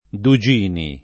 [ du J& ni ]